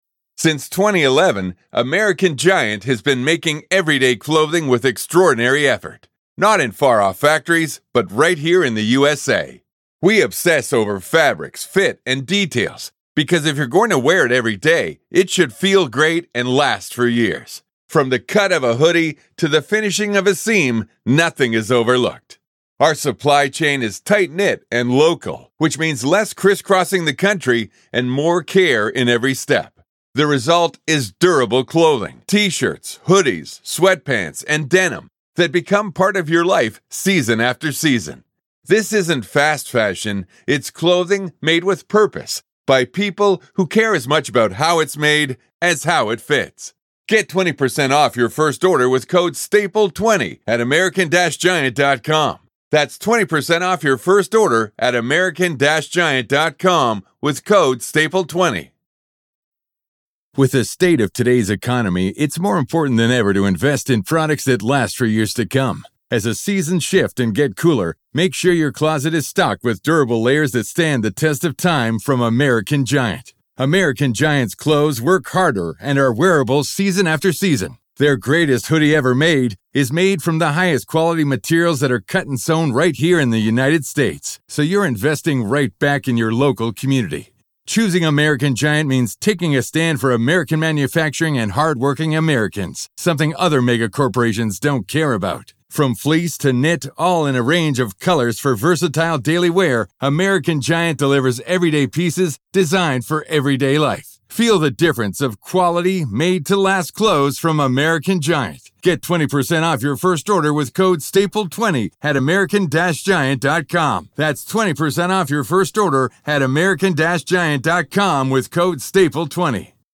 In this Hidden Killers interview